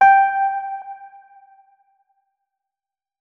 electric_piano
notes-55.ogg